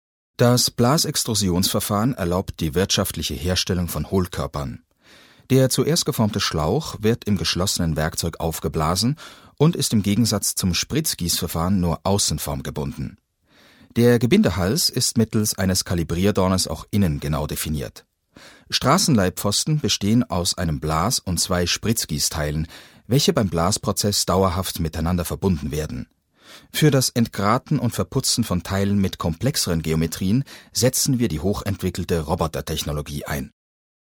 Grosse Bandbreite und Wandelbarkeit.
Der Aufnahme- und Regieraum ist zu 100% schalldicht.
Sprechprobe: Industrie (Muttersprache):